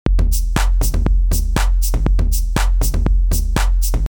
SOS Tutorial 143 Ableton Live - Synth Drums mit Vocoder und Operator